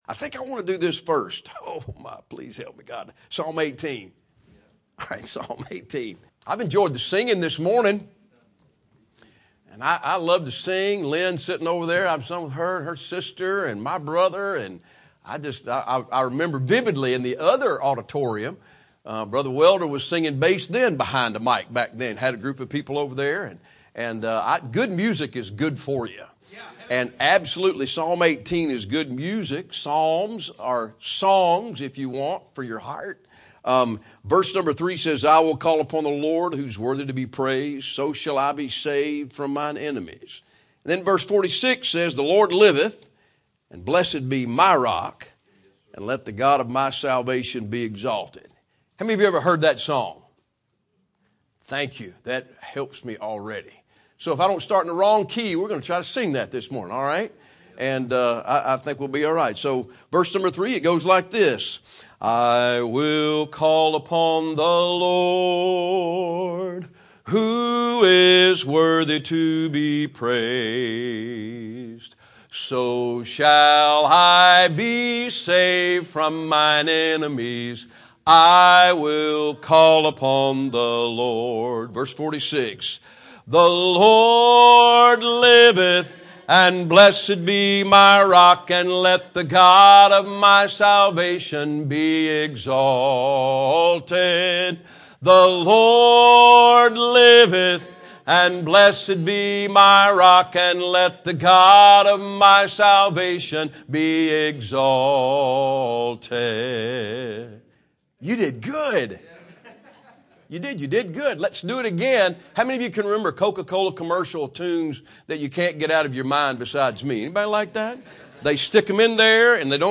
Sermons
missions conference